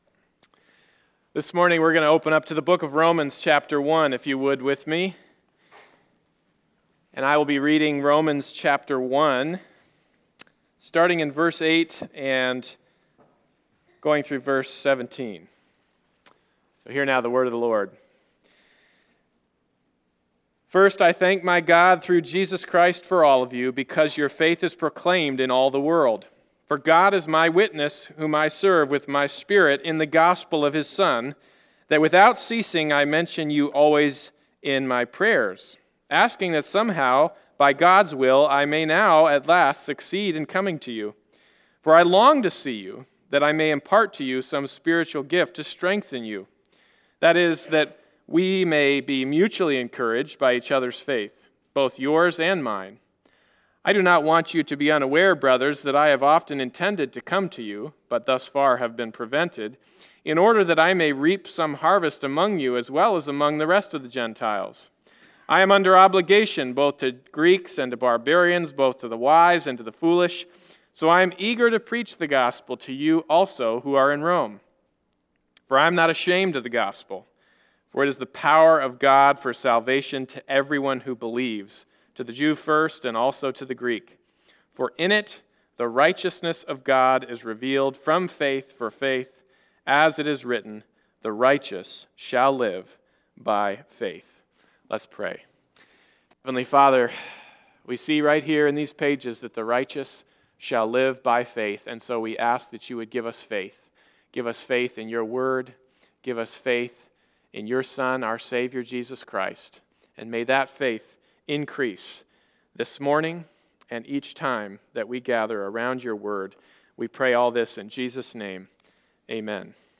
Sermon preached on Highview's 25th Anniversary weekend.
Passage: Romans 1:8-17 Service Type: Sunday Morning Service Sermon preached on Highview's 25th Anniversary weekend.